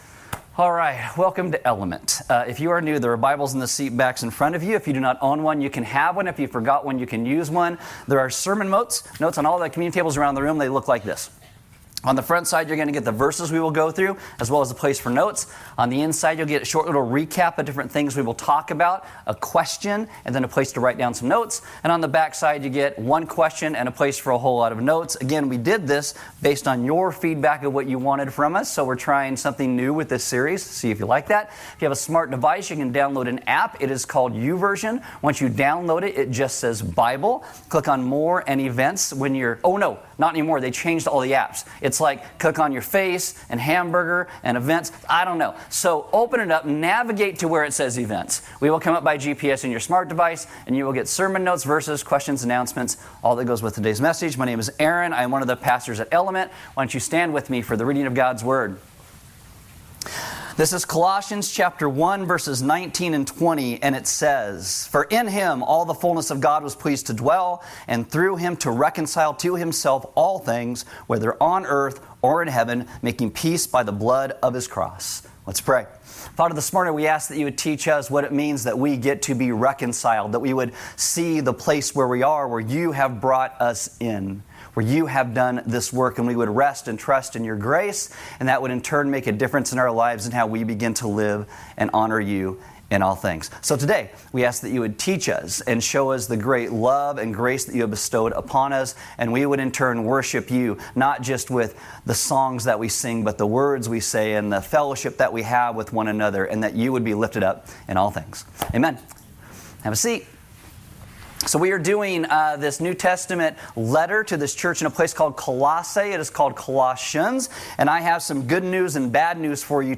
A message from the series "Colossians." This is our second week looking specifically at what it means that Jesus is over all things (the Creator, the supreme, the highest).